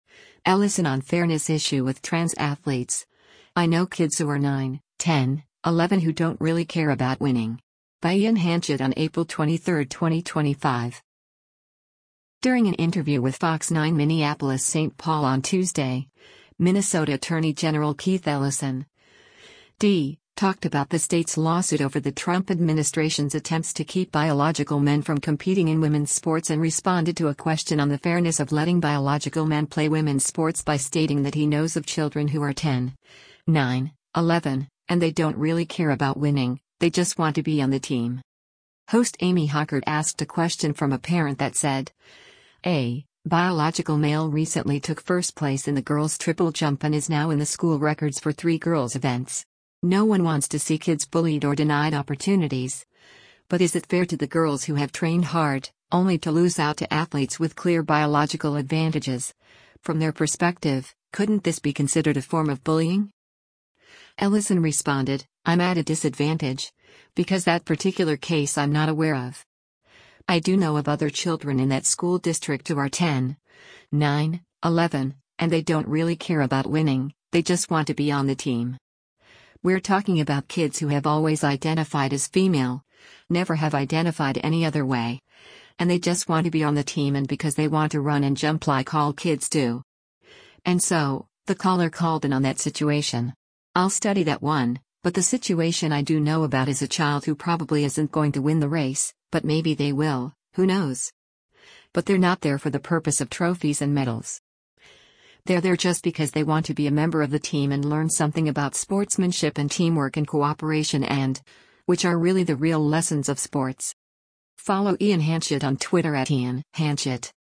During an interview with Fox 9 Minneapolis-St. Paul on Tuesday, Minnesota Attorney General Keith Ellison (D) talked about the state’s lawsuit over the Trump administration’s attempts to keep biological men from competing in women’s sports and responded to a question on the fairness of letting biological men play women’s sports by stating that he knows of children “who are 10, 9, 11, and they don’t really care about winning, they just want to be on the team.”